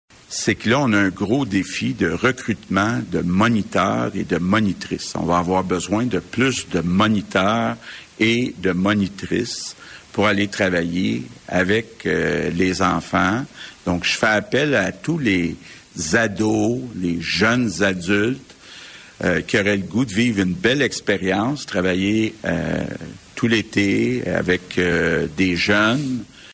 Le premier ministre François Legault prévient que cette décision pose un défi :